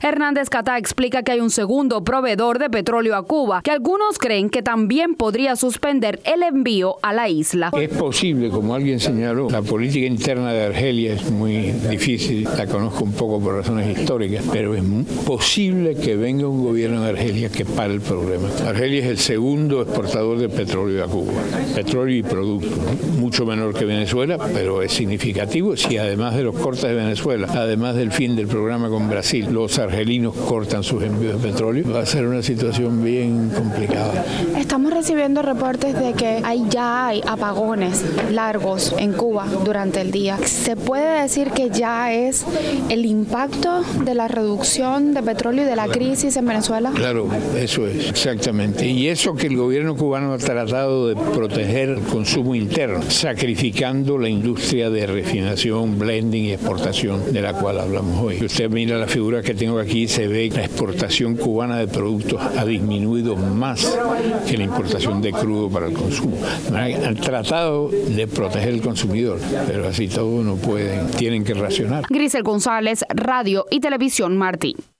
Conferencia ASCE-Cuba